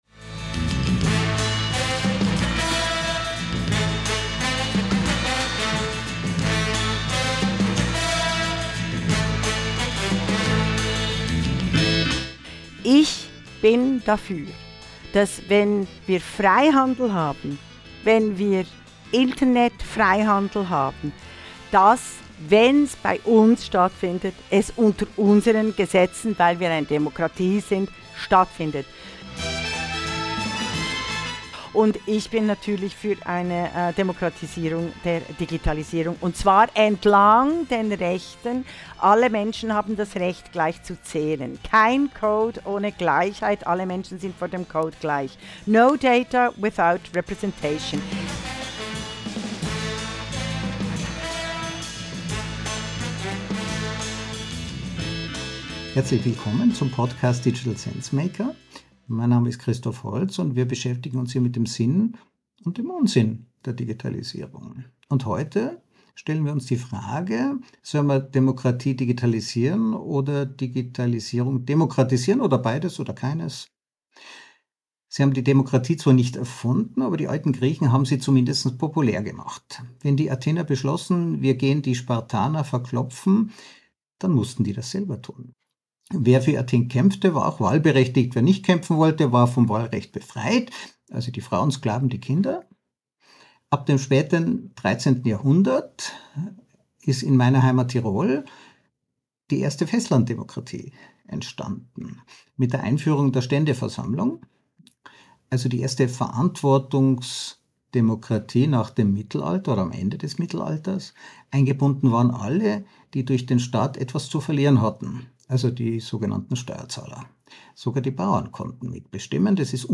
Im selbstfahrenden Auto.